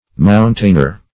Mountainer \Moun"tain*er\, n.
mountainer.mp3